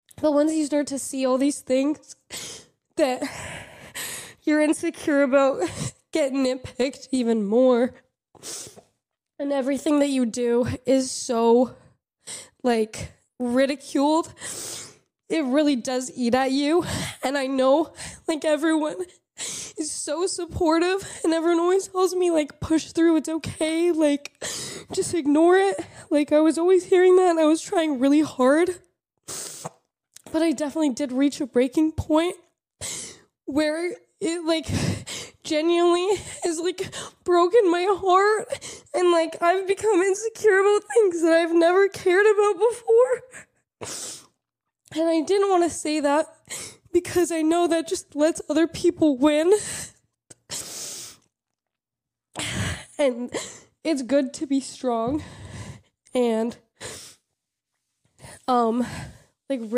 cries while announcing